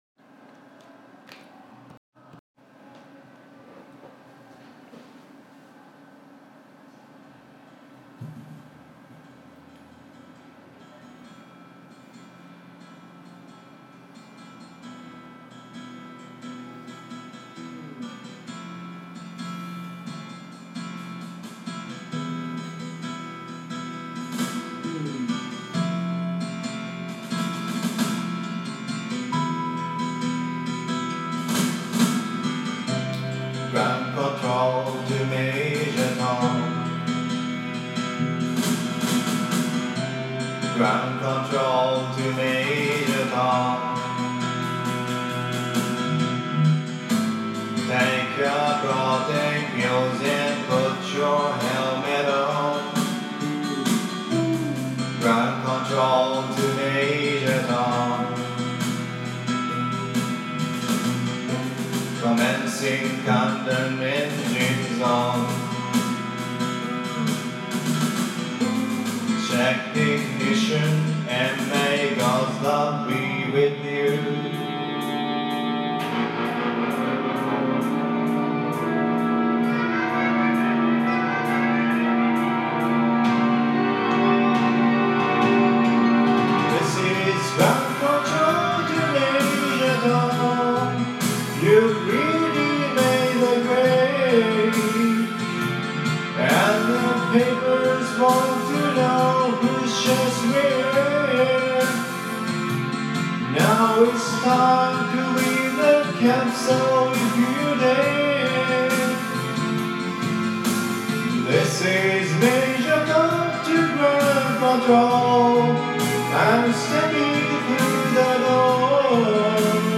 Karaoke version.